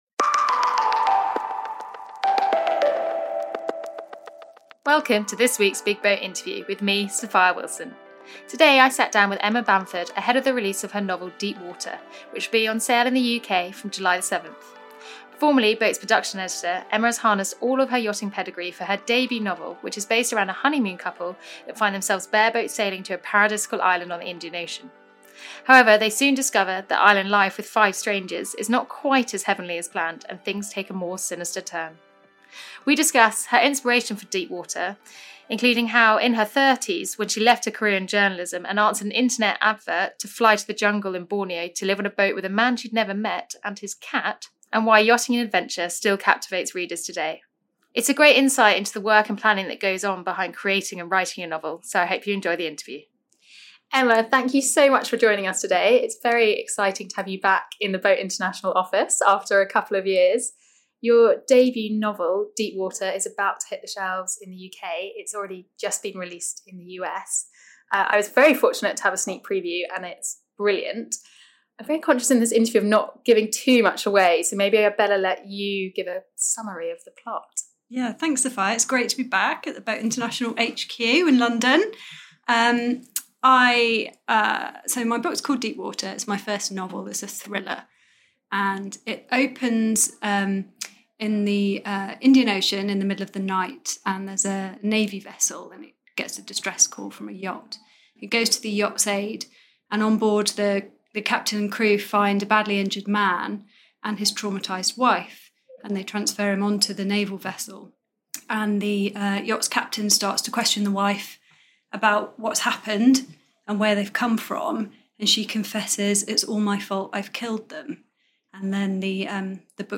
The Big BOAT Interview